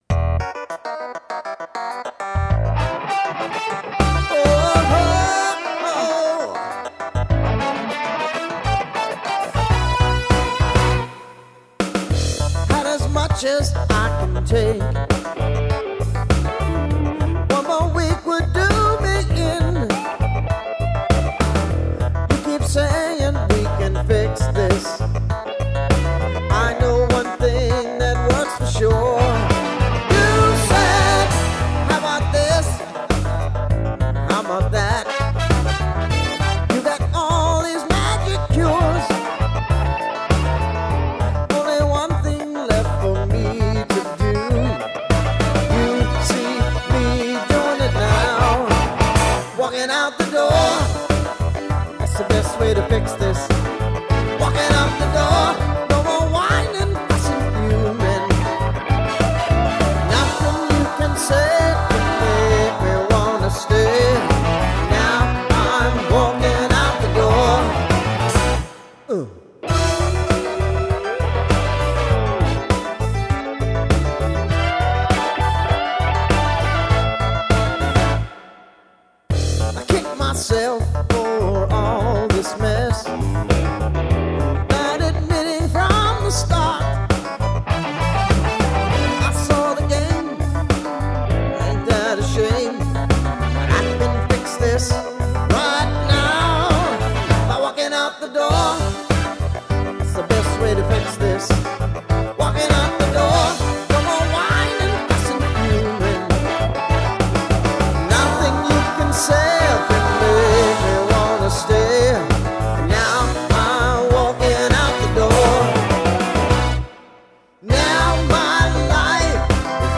Тип альбома: Студийный
Жанр: Blues-Rock, Soft-Rock